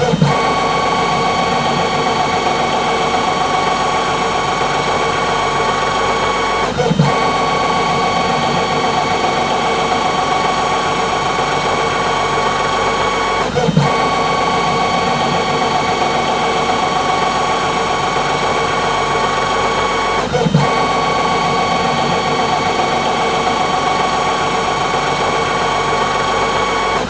CopyMachine_4.wav